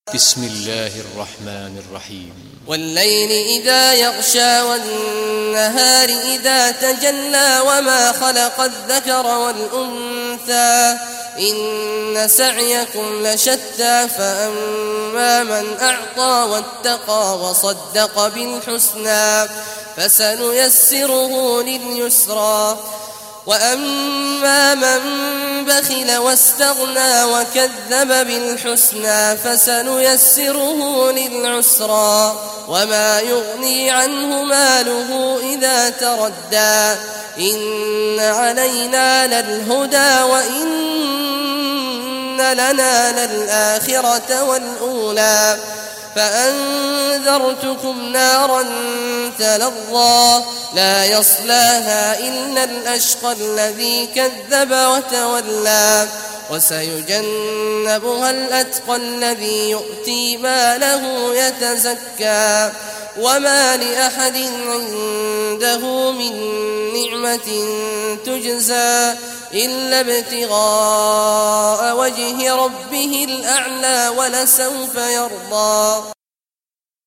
Surah Al-Layl Recitation by Sheikh Awad al Juhany
Surah Al-Layl, listen or play online mp3 tilawat / recitation in Arabic in the beautiful voice of Sheikh Abdullah Awad al Juhany.